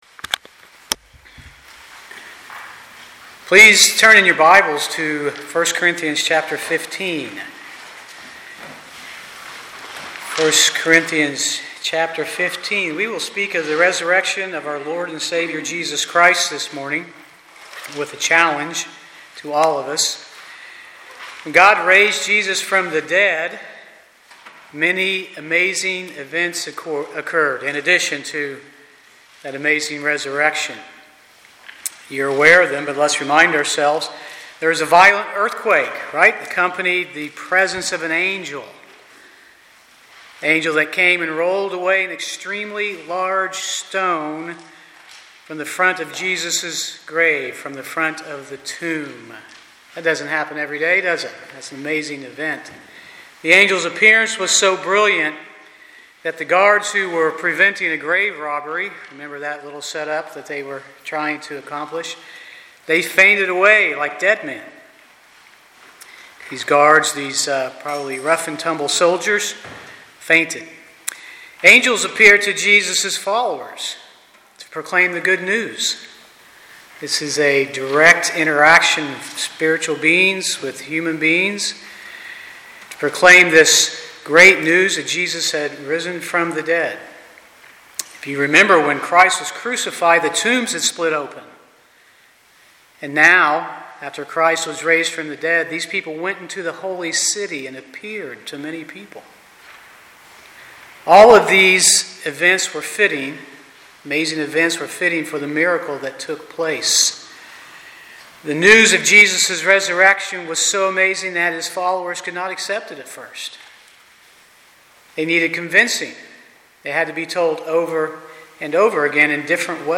Passage: 1 Corinthians 15:1-23 Service Type: Sunday morning « Studies in the Book of Revelation #4C